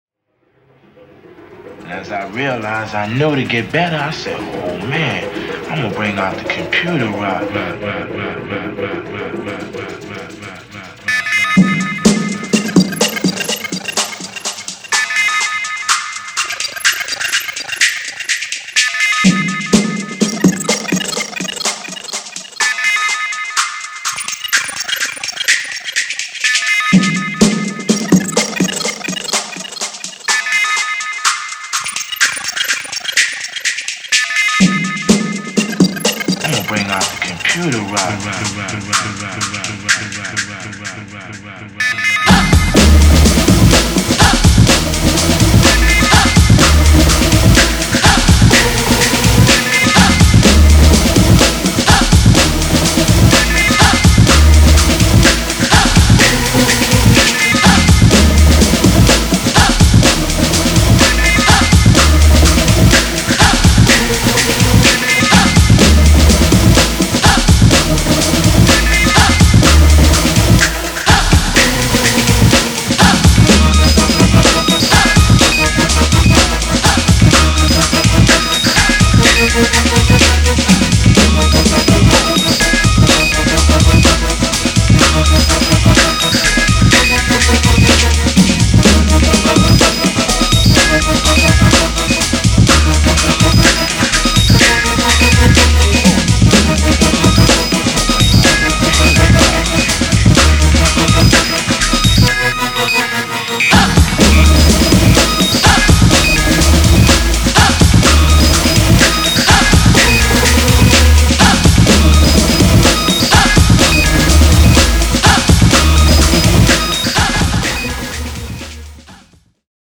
クラシカルなブレイクビーツが醸し出すヴィンテージな輝き、70年代カルトSF映画